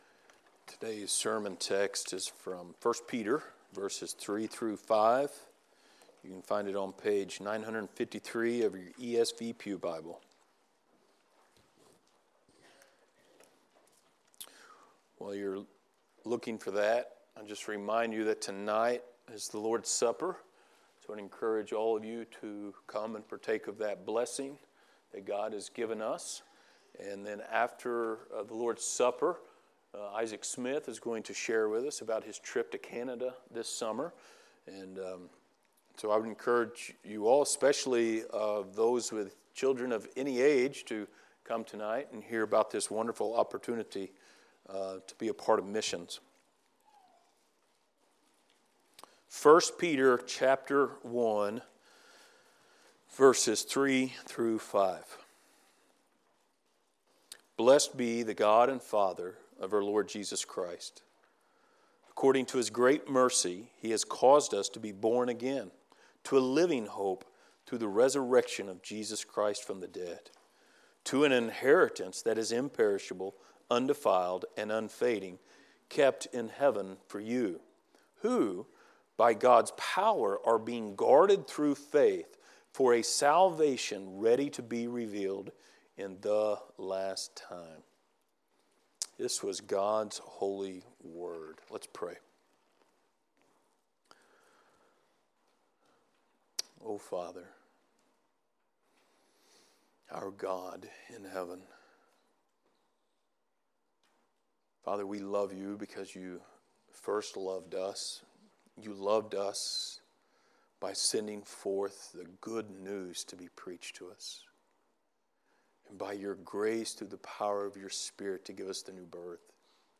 Passage: 1 Peter 1:3-5 Service Type: Sunday Morning